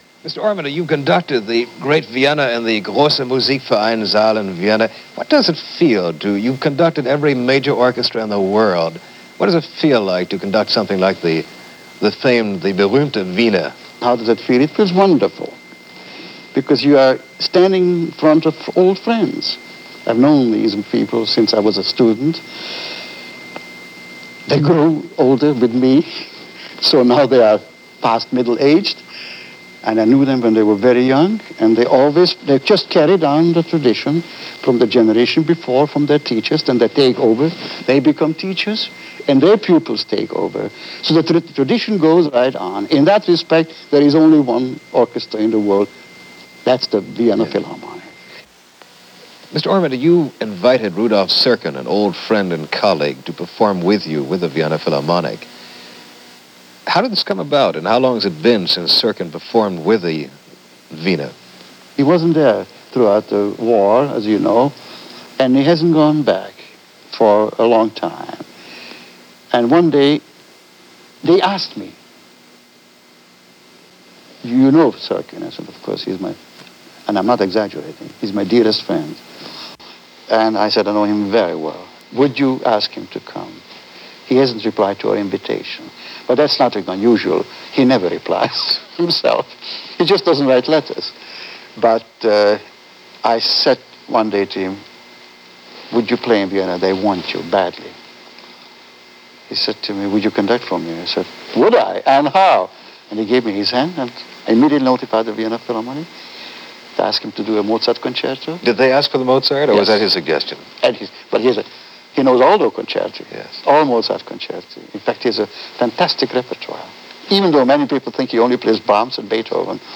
A word or two with Eugene Ormandy - 1971 - an interview conducted for Fanfare on February 1, 1971 - Past Daily Talking Music.
Interview-with-Eugene-Ormandy-1971.mp3